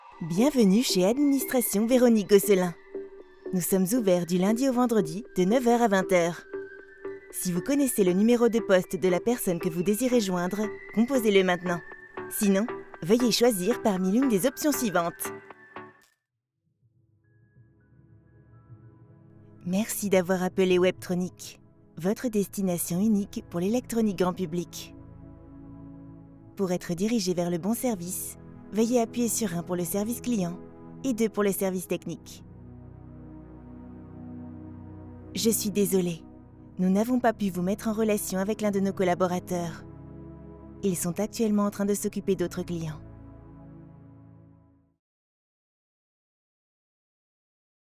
Comercial, Joven, Travieso, Cálida, Suave
Telefonía
She has a medium voice.